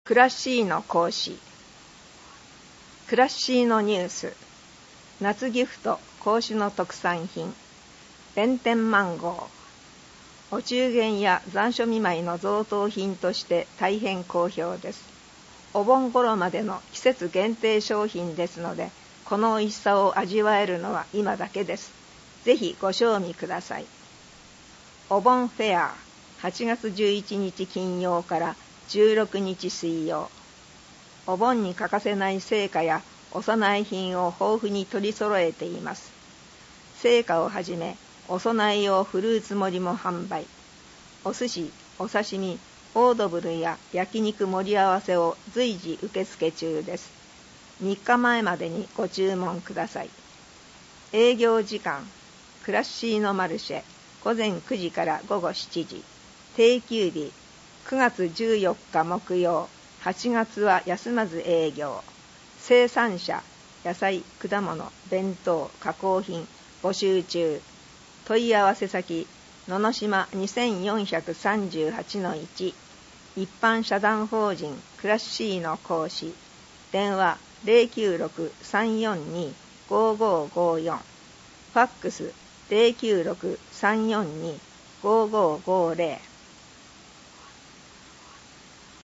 広報こうし令和5年8月号 音訳版